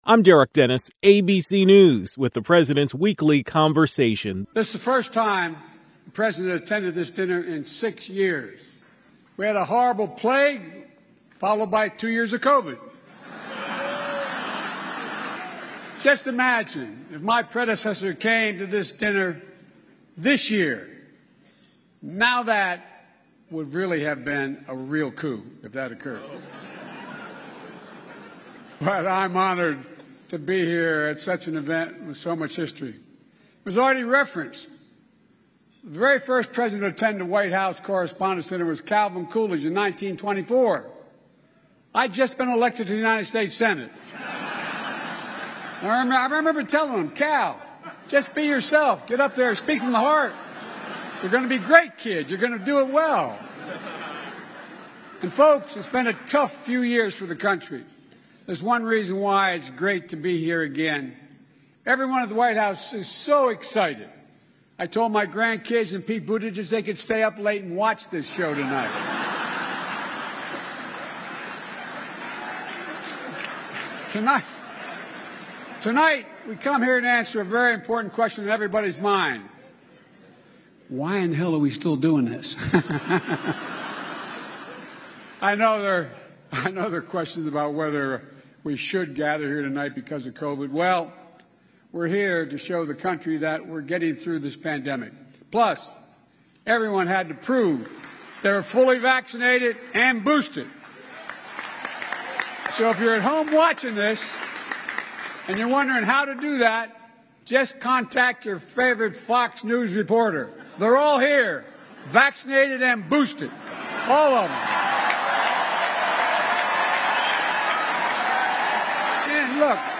President Biden spoke at the White House Correspondence Dinner.